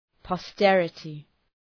{pɒ’sterətı}